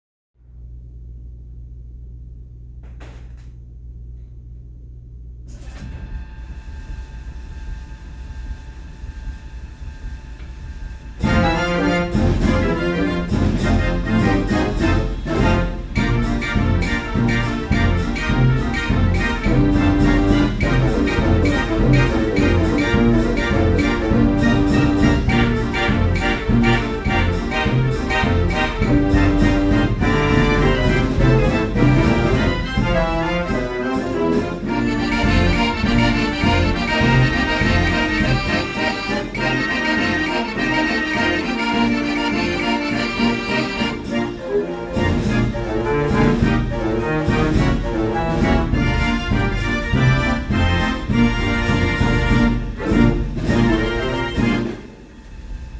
Mortier Cafe Organ 1930 - Stahls Automotive Collection
This instrument was a self-contained unit designed to play background music or dance music in European cafes and dance halls in the early 20th century.
There are approximately 600 pipes and percussion traps, this would have originally played from rigid cardboard books with hole perforations, like a player piano roll.
Mortier-Cafe-Organ.mp3